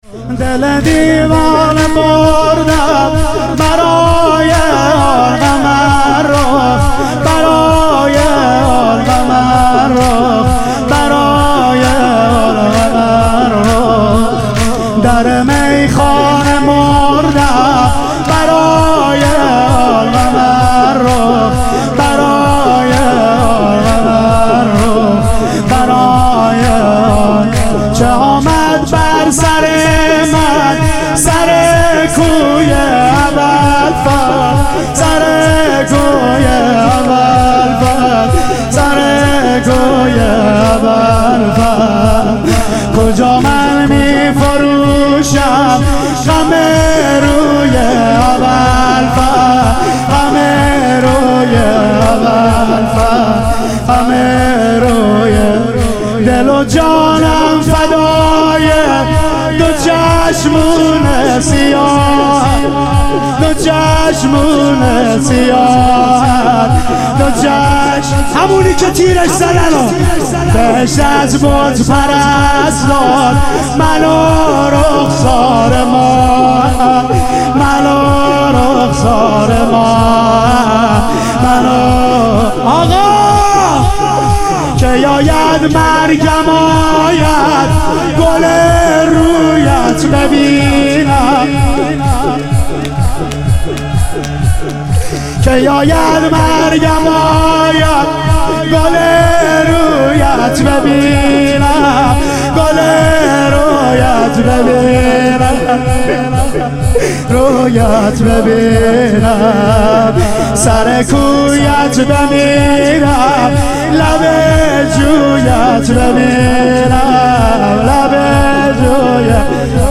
ظهور وجود مقدس حضرت عباس علیه السلام - تک